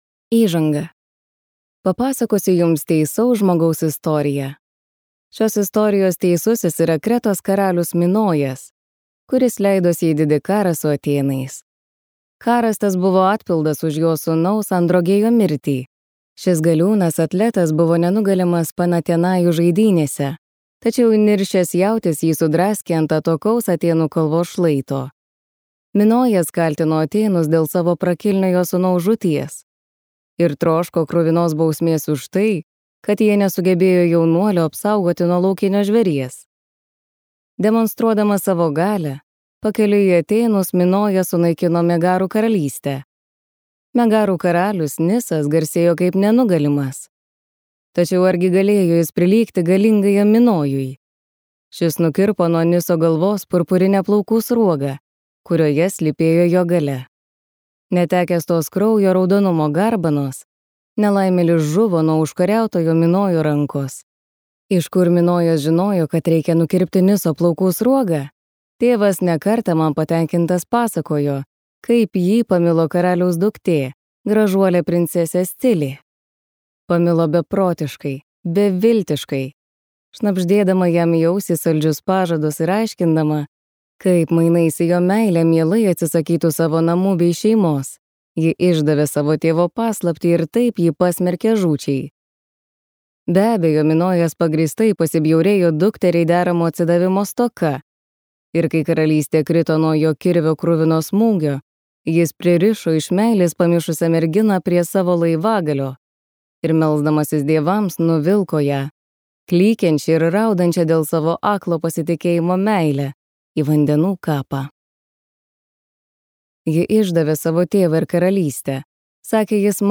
Ariadnė | Audioknygos | baltos lankos